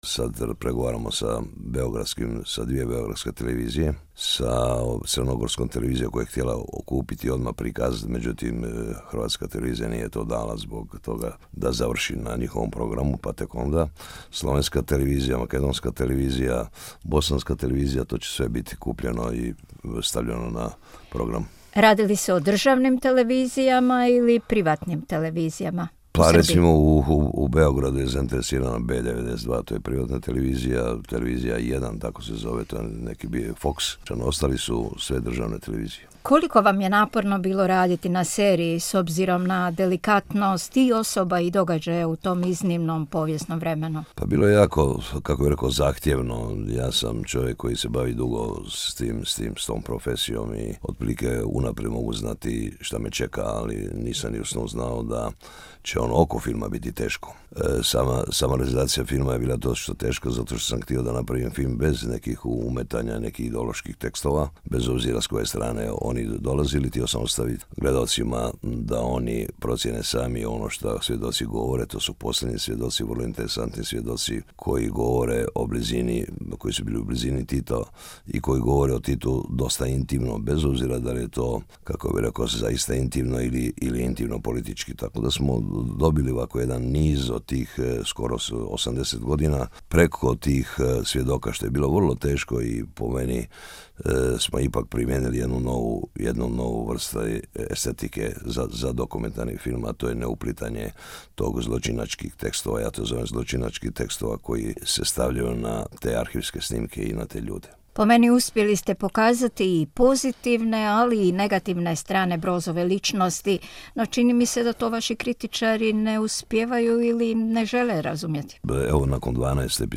Razgovor sa Lordanom Zafranovićem